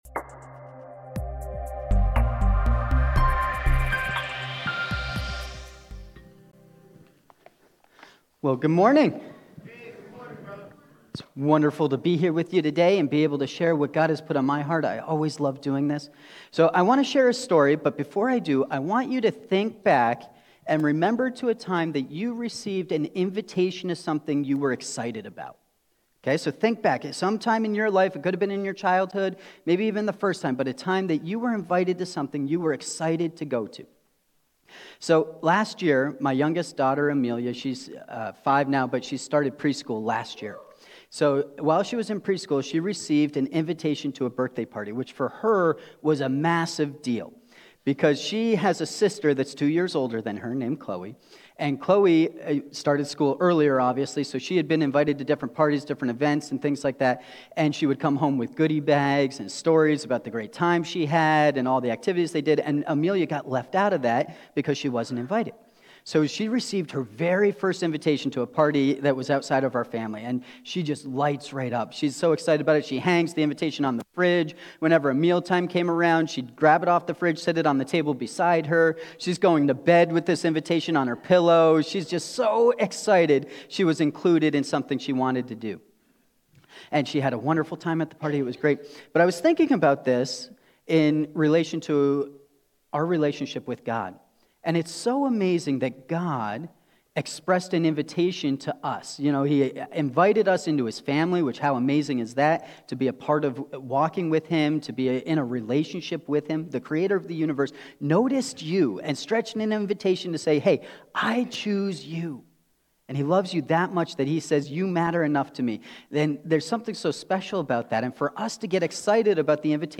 Youre-Invited-Sermon.mp3